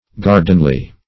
gardenly - definition of gardenly - synonyms, pronunciation, spelling from Free Dictionary Search Result for " gardenly" : The Collaborative International Dictionary of English v.0.48: Gardenly \Gar"den*ly\, a. Like a garden.